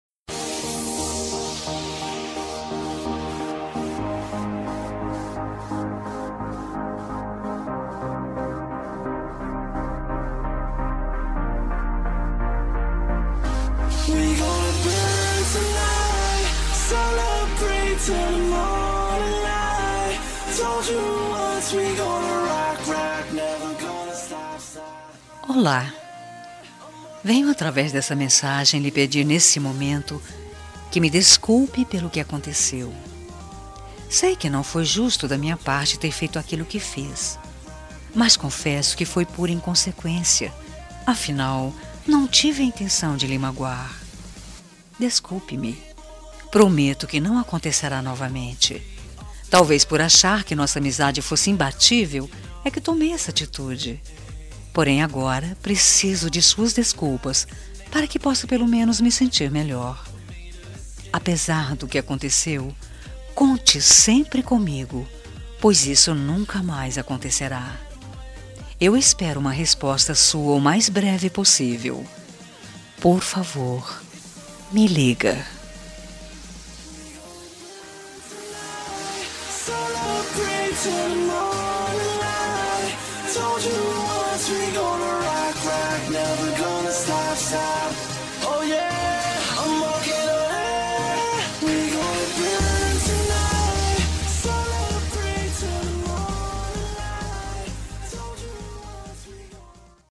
Telemensagem de Desculpas – Voz Feminina – Cód: 202034